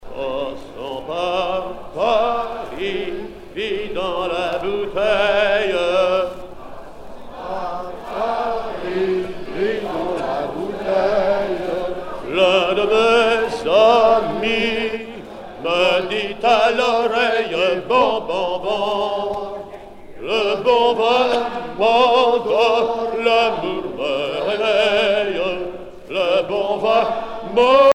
gestuel : à virer au cabestan
Genre laisse
Pièce musicale éditée